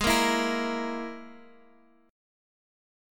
G#sus2#5 chord